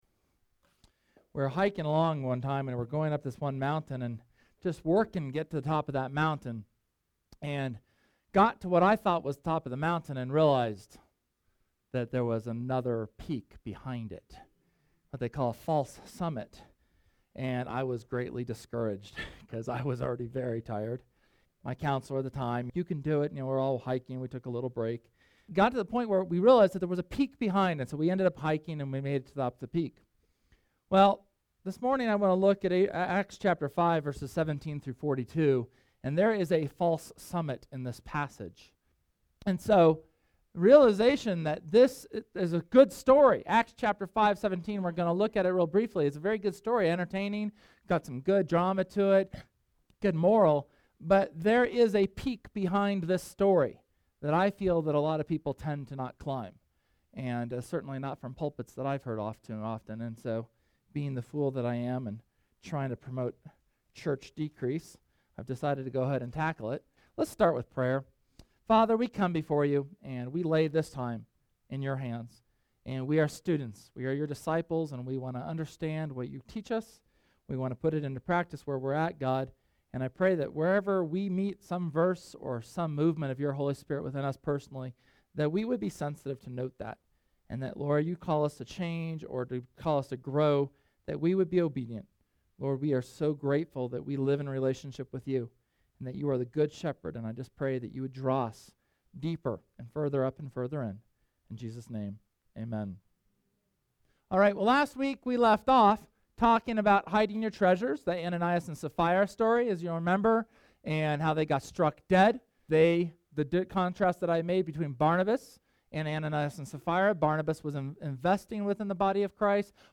SERMON: Fear God & Obey His Commands